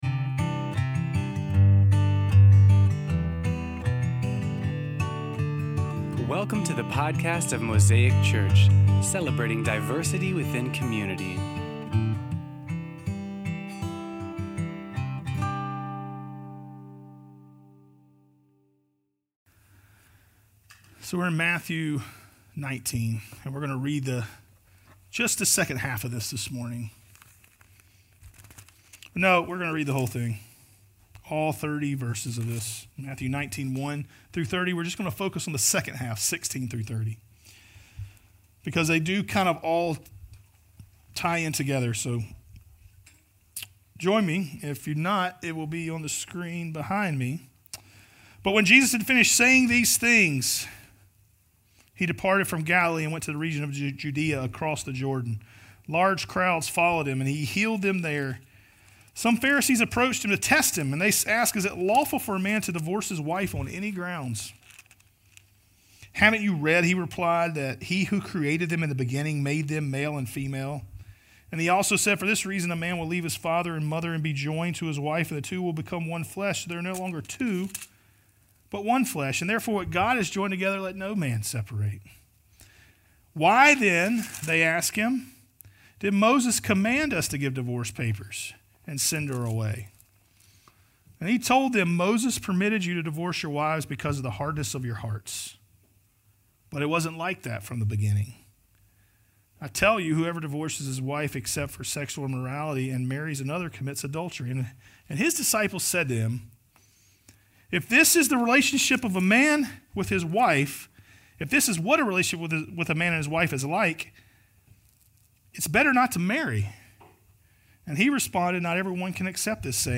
Sermon Series on Matthew's Gospel